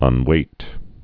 (ŭn-wāt)